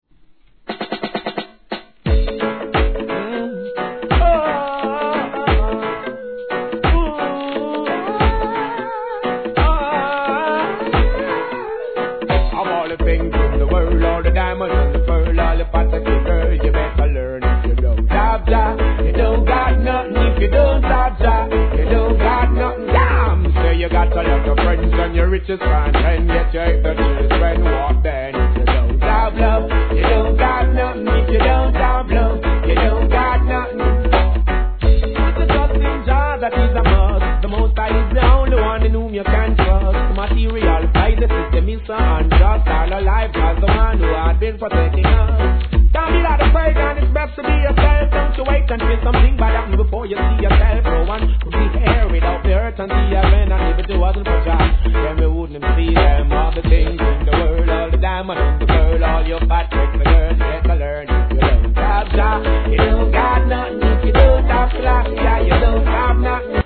REGGAE
力を抜いたリズムカルのフロウがいい感じではまります♪